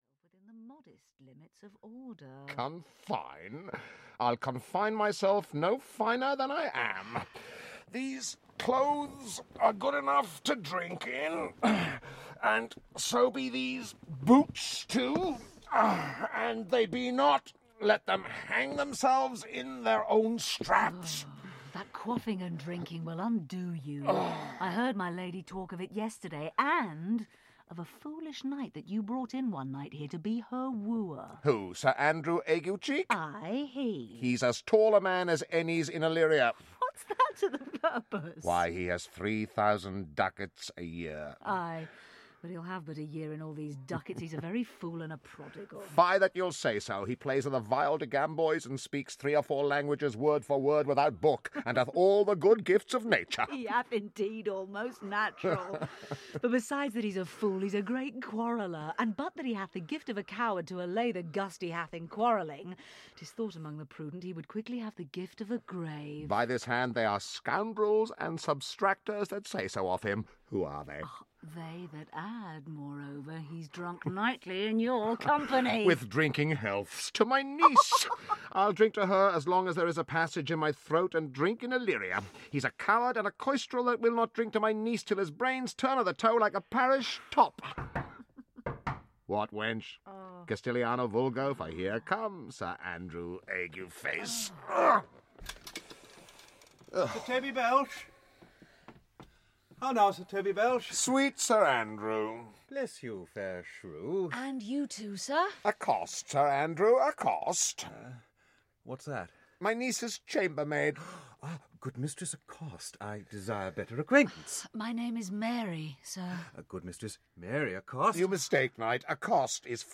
Twelfth Night (EN) audiokniha
Ukázka z knihy
Twelfth Night is part of Naxos AudioBooks’ exciting new series of complete dramatisations of the works of Shakespeare, in conjunction with Cambridge University Press.